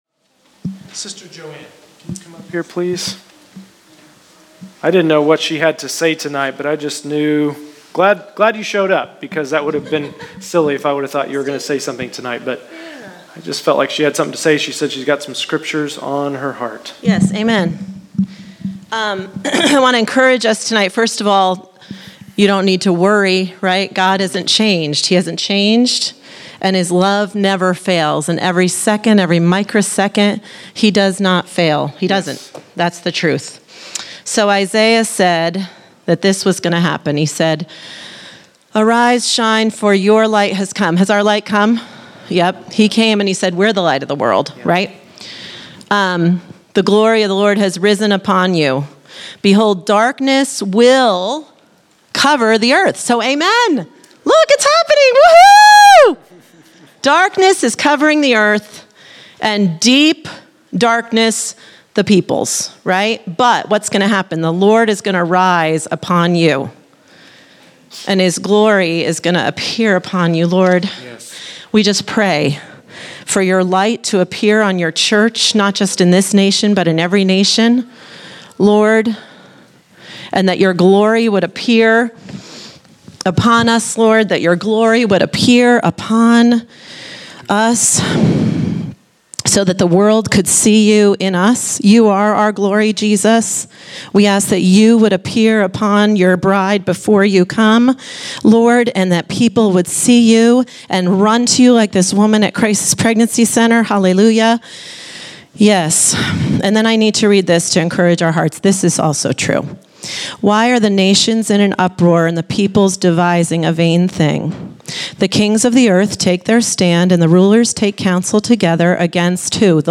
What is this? Category: Scripture Teachings